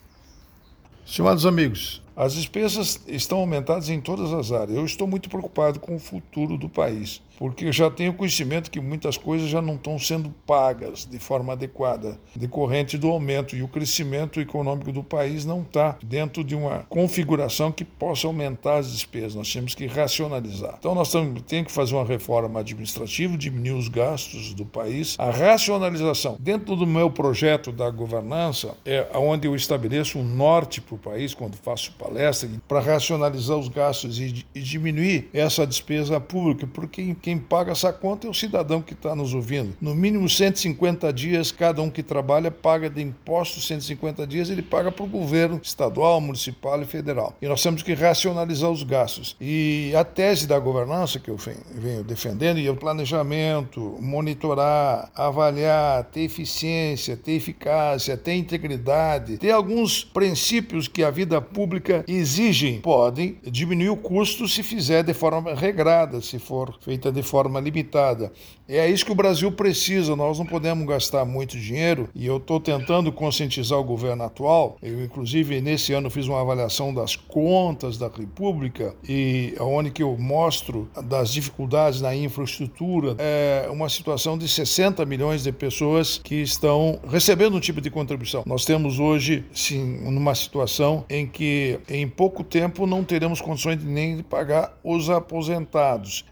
Comentário do ministro do Tribunal de Contas da União, Augusto Nardes.